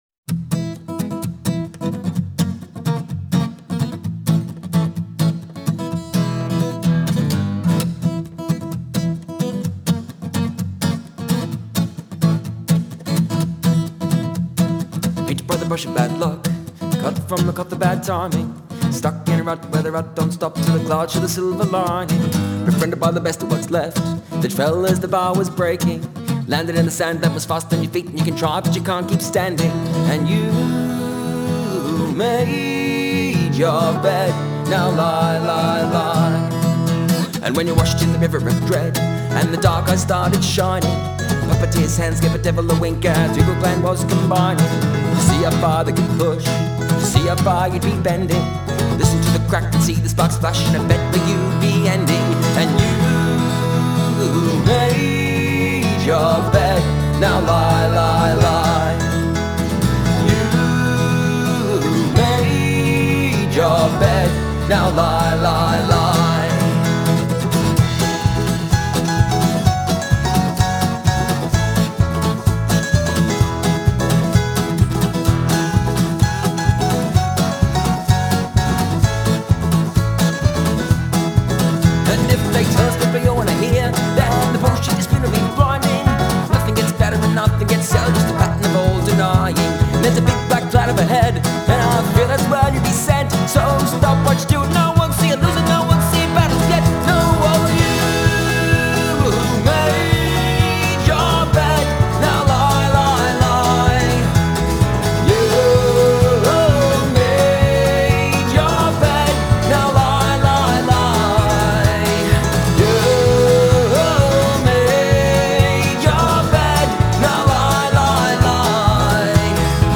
Genre: Folk, Roots, Alternative